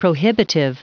Prononciation du mot prohibitive en anglais (fichier audio)
Prononciation du mot : prohibitive